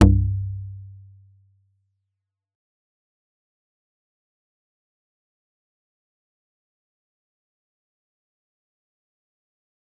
G_Kalimba-C2-f.wav